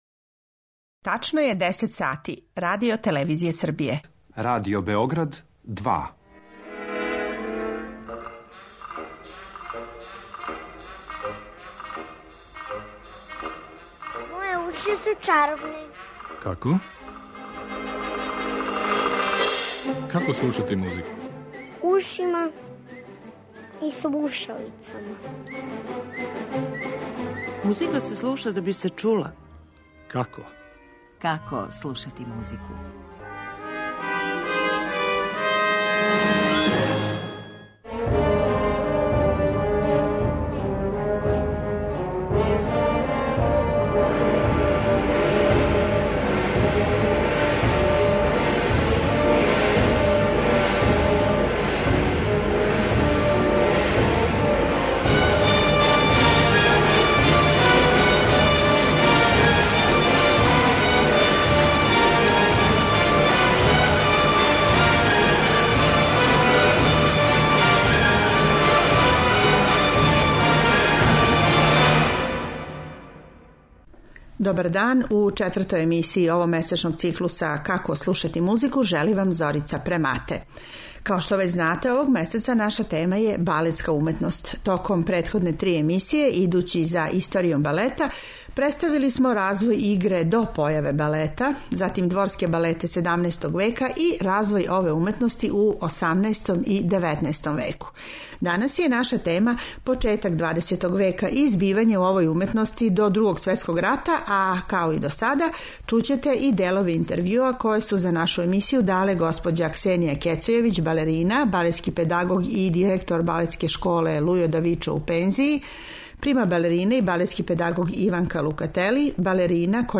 У емисији ћемо емитовати музику најпознатијих балета прве половине 20. века, а из пера Стравинског, Прокофјева, Пуленка, Дебисија, Сатија и Равела.